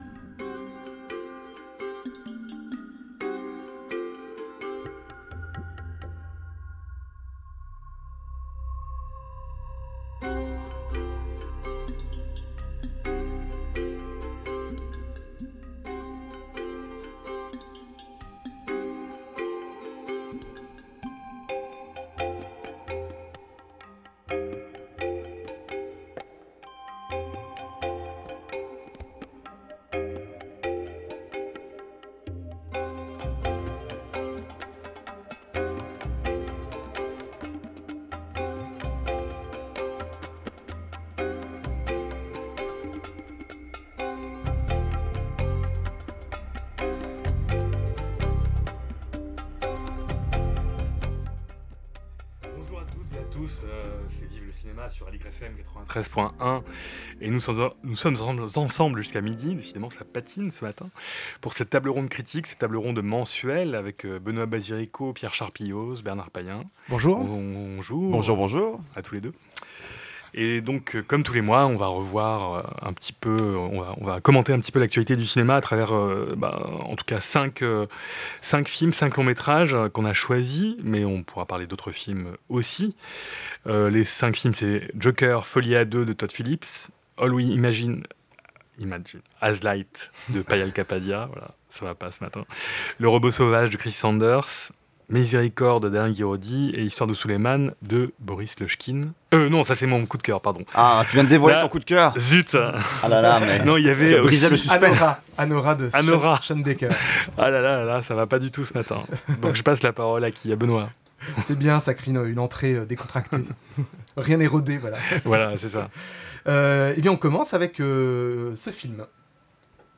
Table ronde critique autour des films